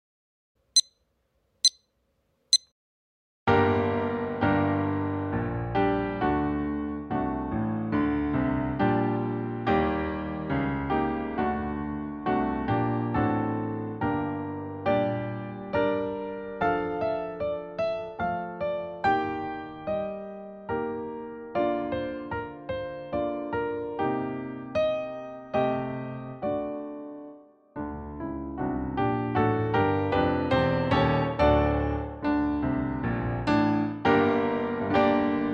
Utwór nagrany bez metronomu
Tempo początkowe: 68 BMP
Repetycja uwzględniona
strój 440Hz
piano